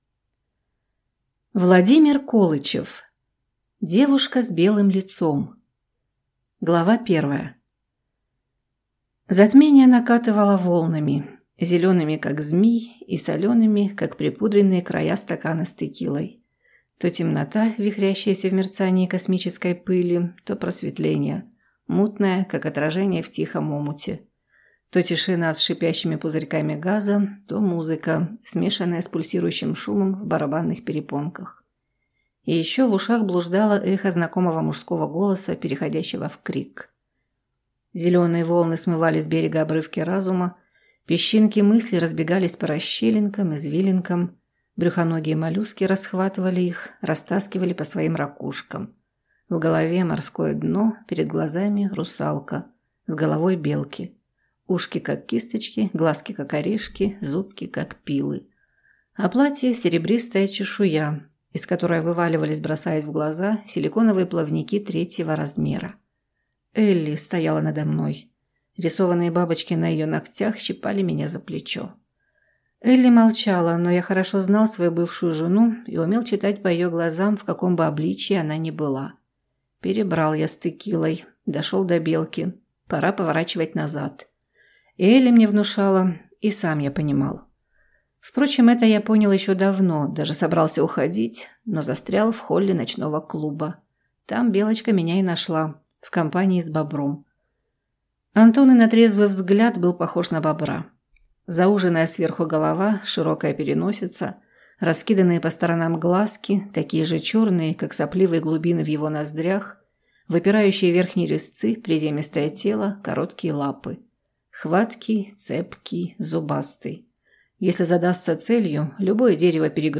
Аудиокнига Девушка с белым лицом | Библиотека аудиокниг